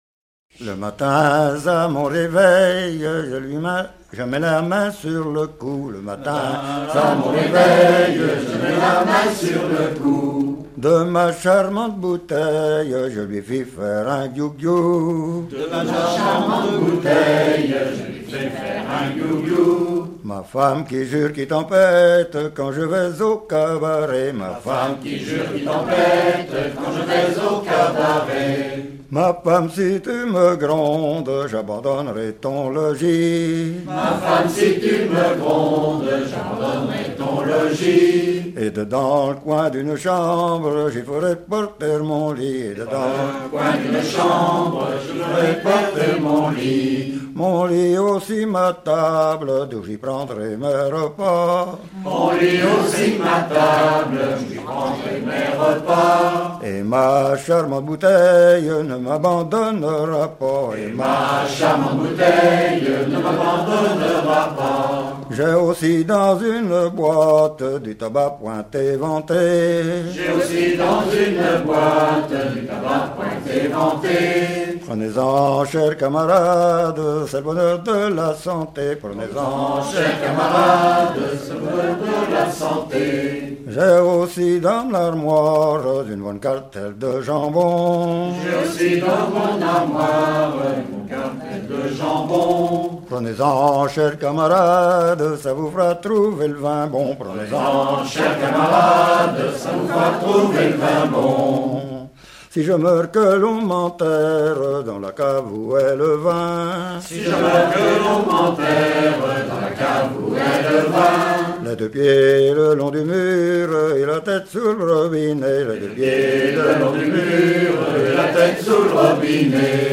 circonstance : bachique
Genre strophique
Pièce musicale éditée